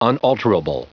Prononciation du mot unalterable en anglais (fichier audio)
Prononciation du mot : unalterable